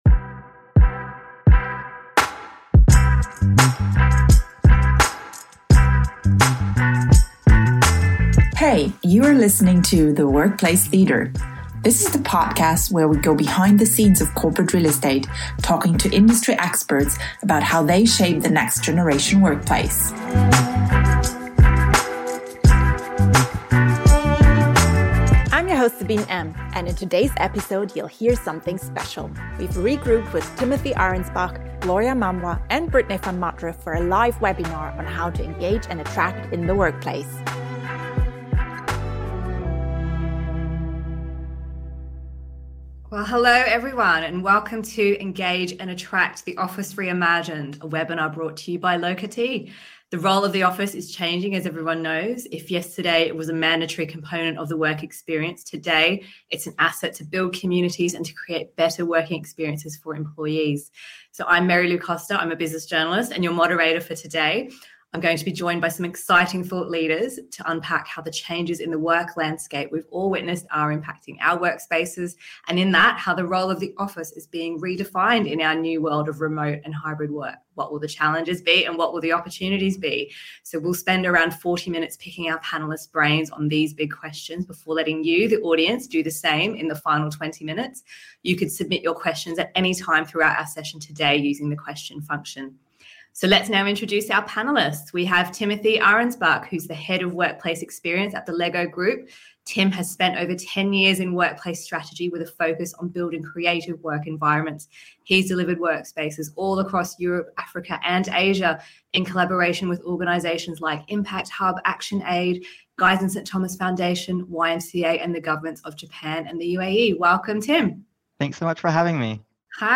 In a live webinar